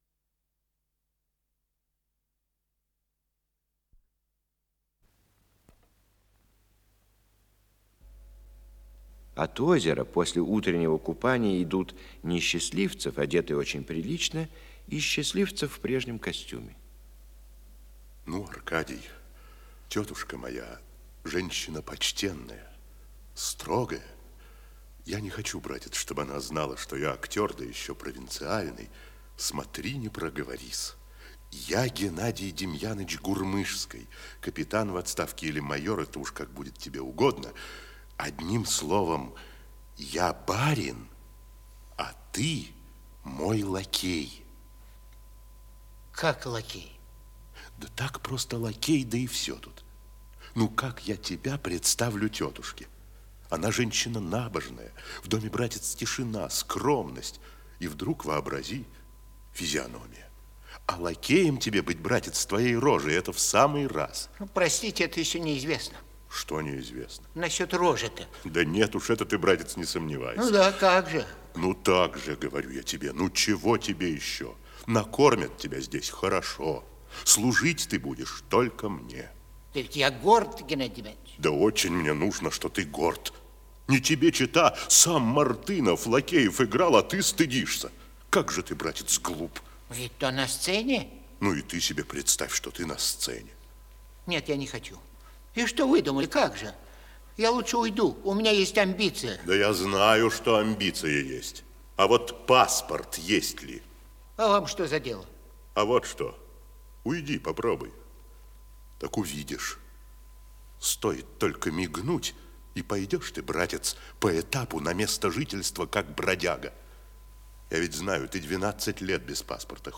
Исполнитель: Артисты Государственного академического Малого театра СССР
Спектакль Государственного академического Малого театра СССР